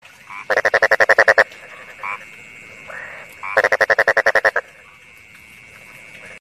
Nada Notif Kodok
Genre: Nada dering binatang
nada-notifikasi-kodok-www_NadaDeringLucu_com.mp3